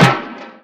Snares
Gone Snare.wav